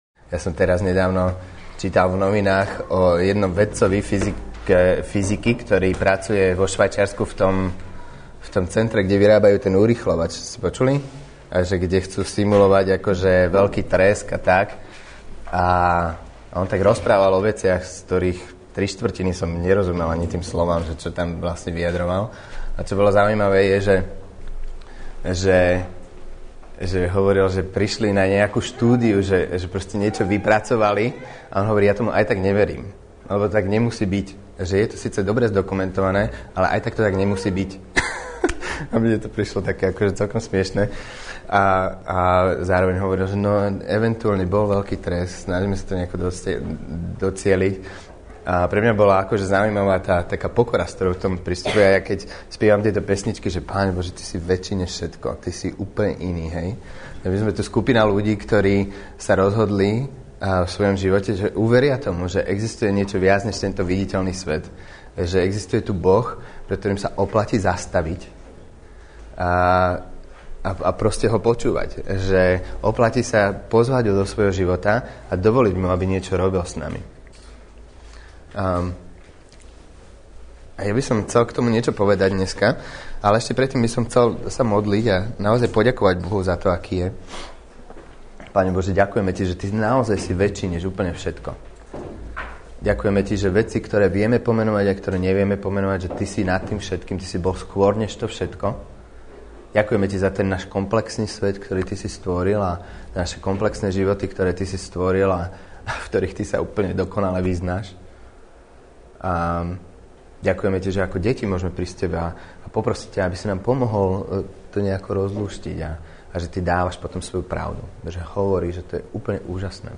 Nahrávka kázne Kresťanského centra Nový začiatok z 5. júla 2009